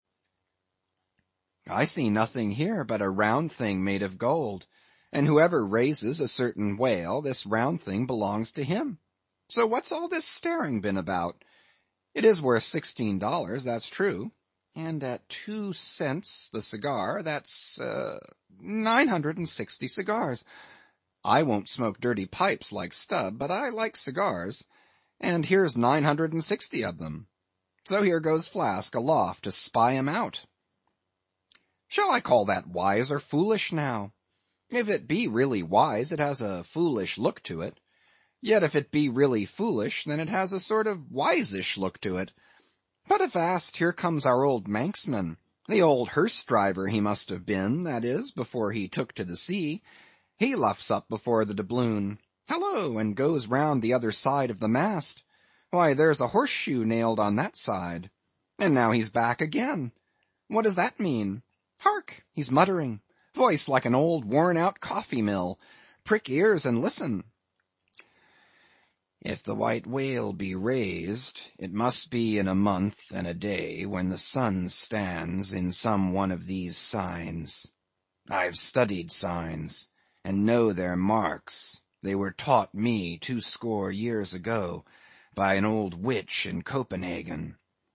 英语听书《白鲸记》第834期 听力文件下载—在线英语听力室